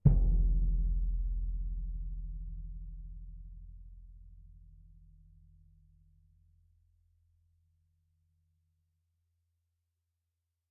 bassdrum_hit_mf1.mp3